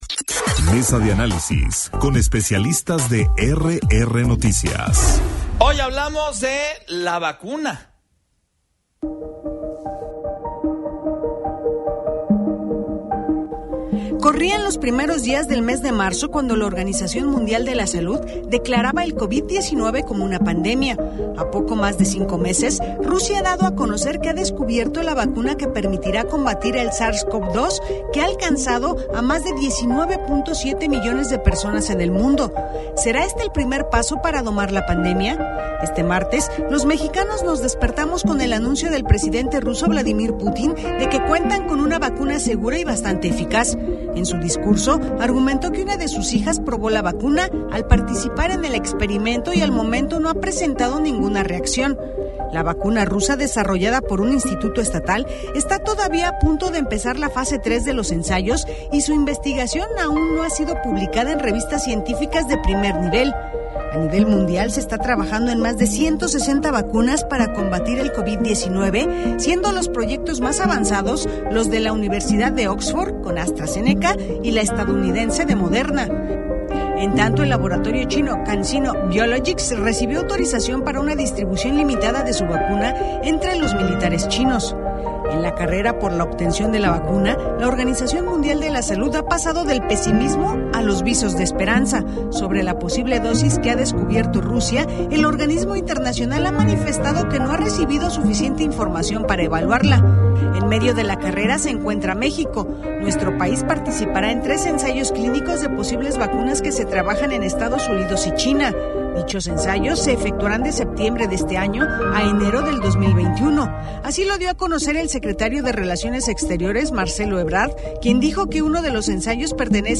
MESA-DE-ANALISIS-POLITICO-120820.mp3